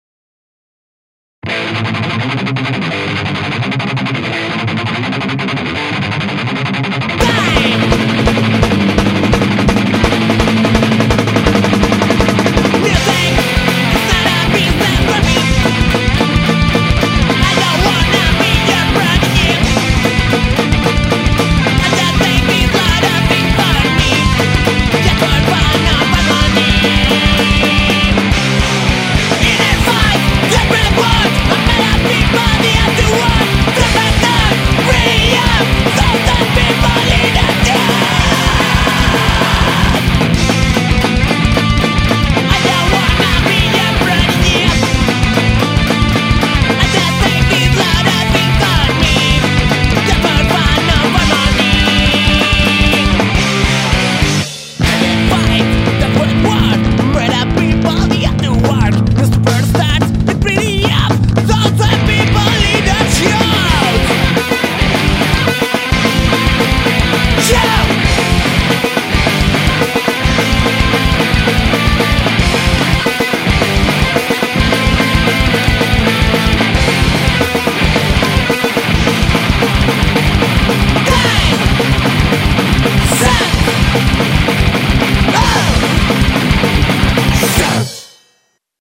Estilo: Punk Rock